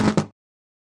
Index of /server/sound/geareffectsounds/rally/shift
down1.wav